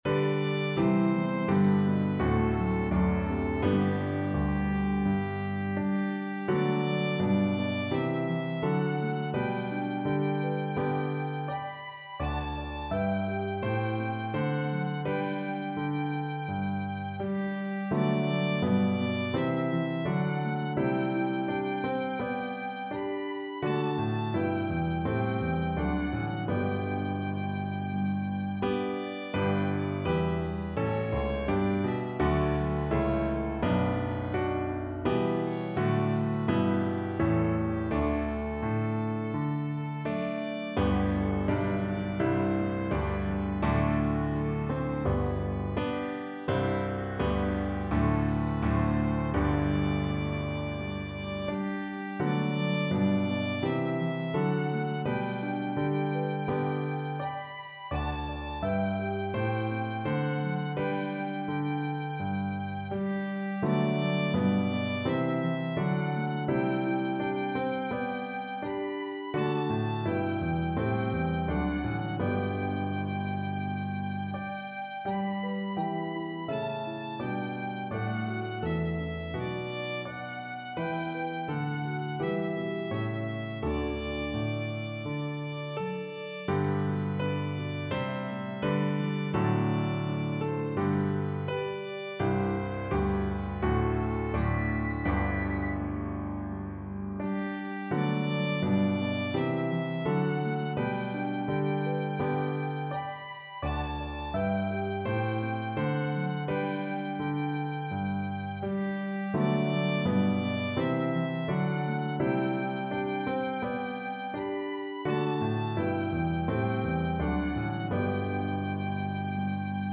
The piano part is also playable on organ or pedal harp.